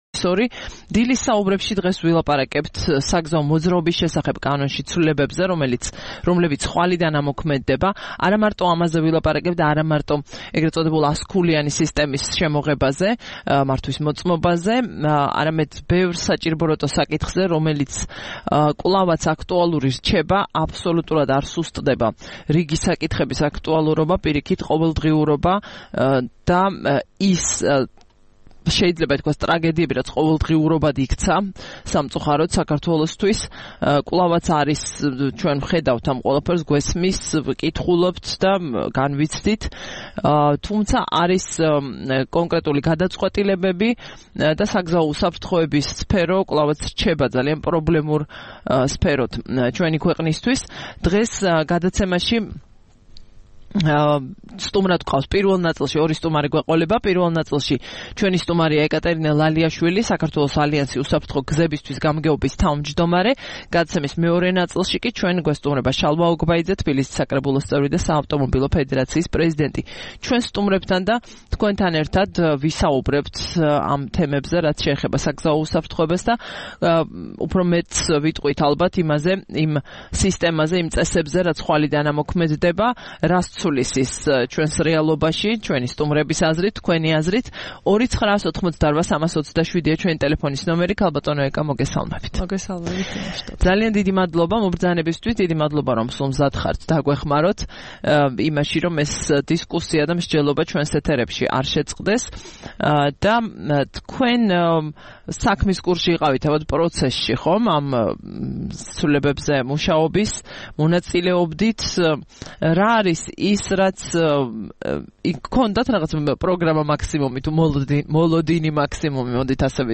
სტუმრად ჩვენს ეთერში: